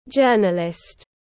Journalist /ˈdʒɜː.nə.lɪst/